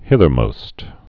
(hĭthər-mōst)